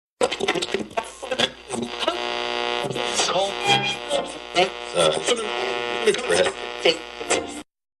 radio-static.5fb7a5be8a84f3f746d2.mp3